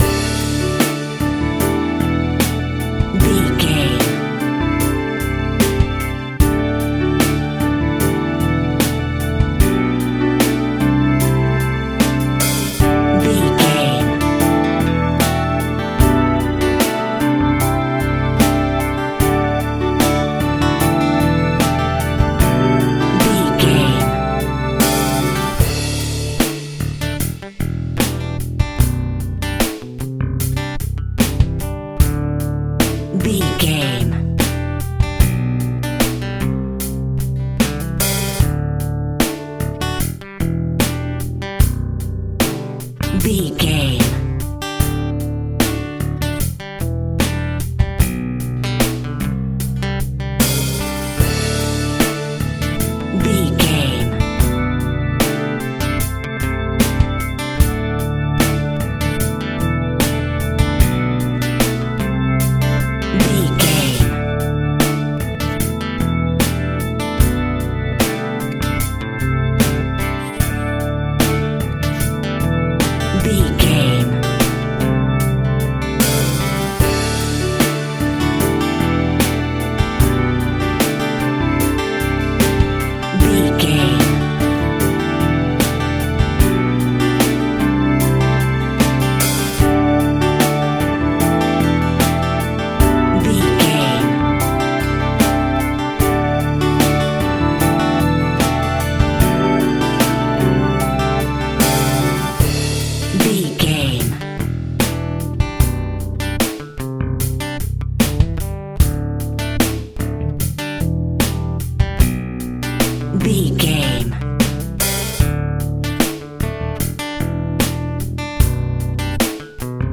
Light Pop Rock.
Ionian/Major
D
fun
energetic
uplifting
acoustic guitar
drums
bass gutiar
piano